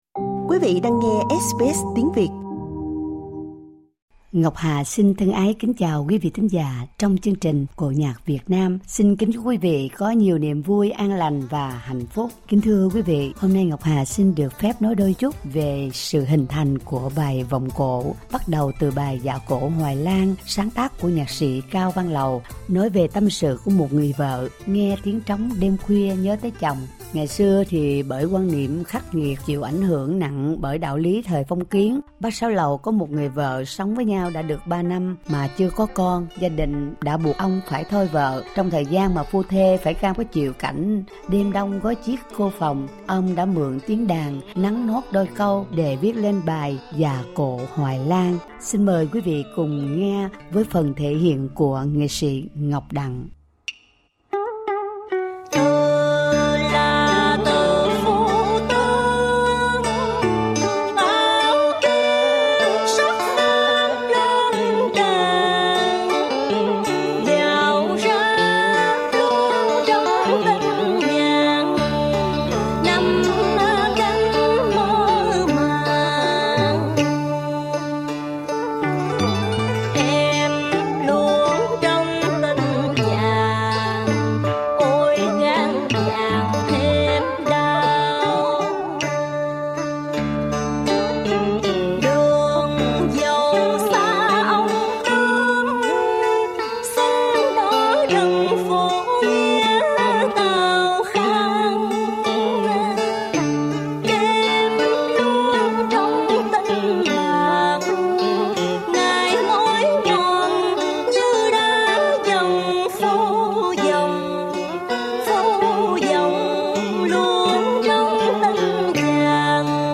Xin mời quý vị cùng nghe bài 'Dạ cổ hoài Lang' đến 'Đêm mưa nhớ Mẹ' vọng cổ nhịp 16.